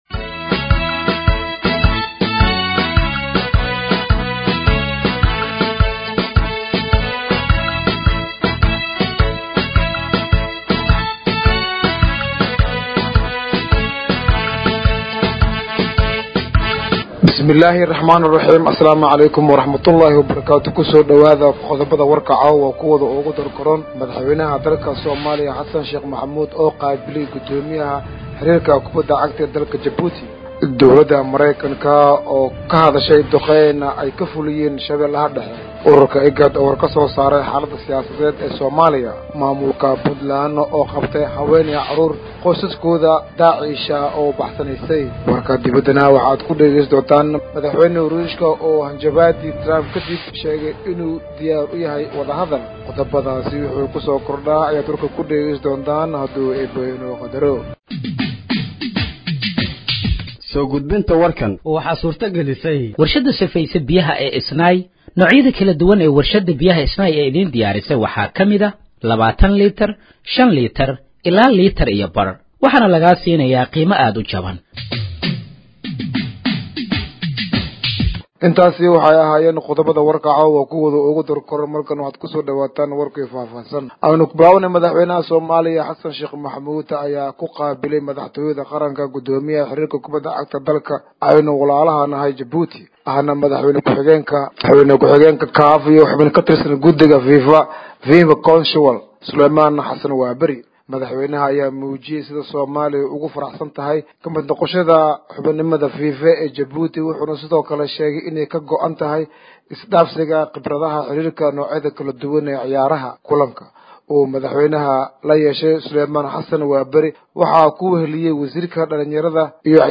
Dhageeyso Warka Habeenimo ee Radiojowhar 22/04/2025